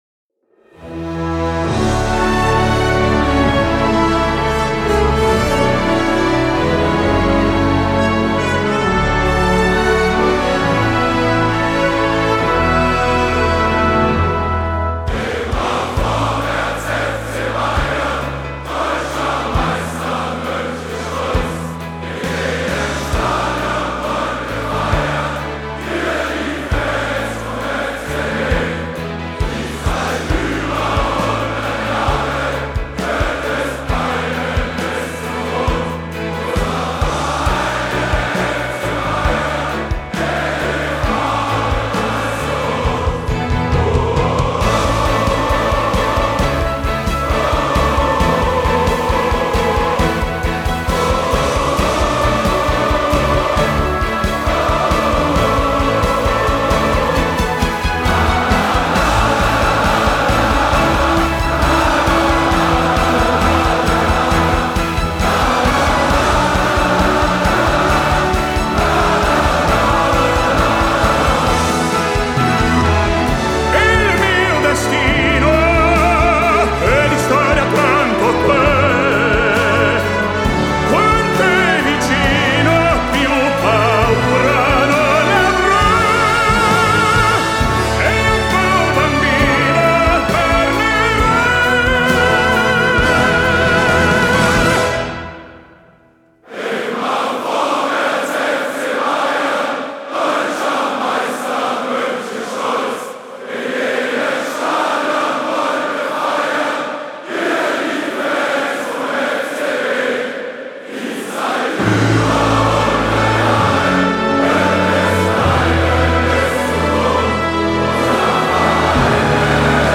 sung by 8,000 fans
tenor